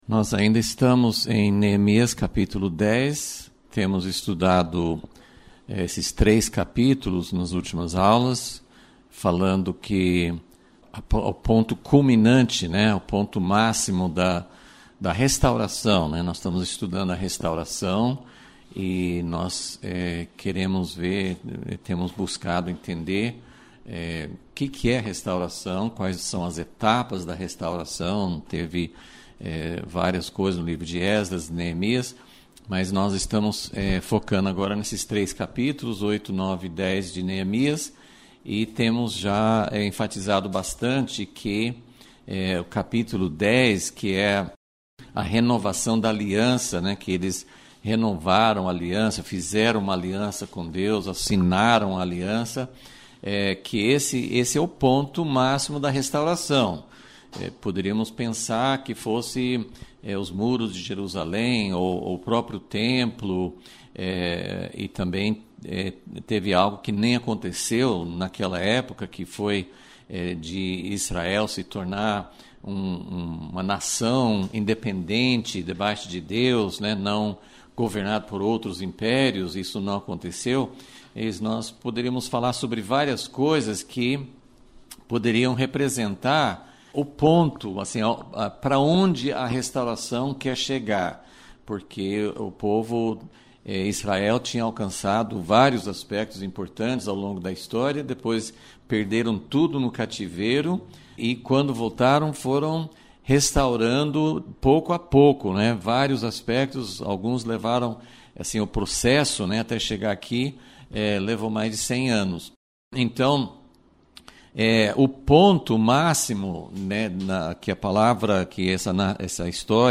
Aula 27 - Vol.36 - O que Deus procura numa Aliança — Impacto Publicações